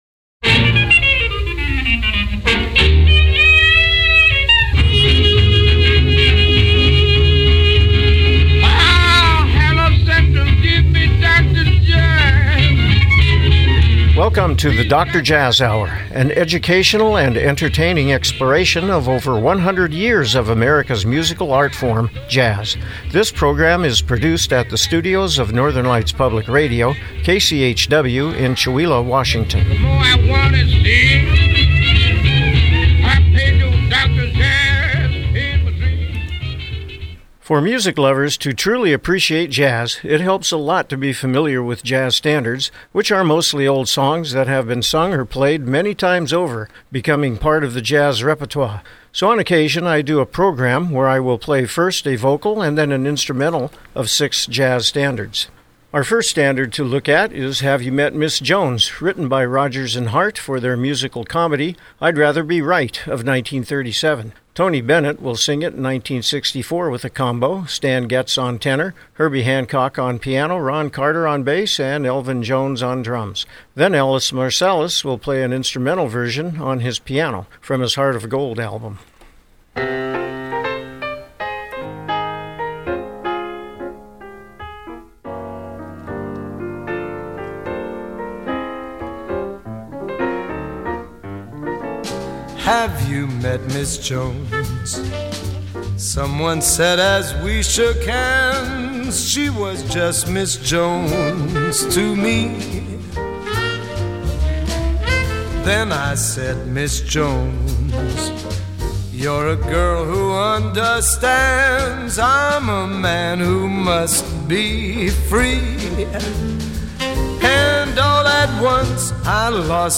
Program Type: Music